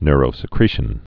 (nrō-sĭ-krēshən, nyr-)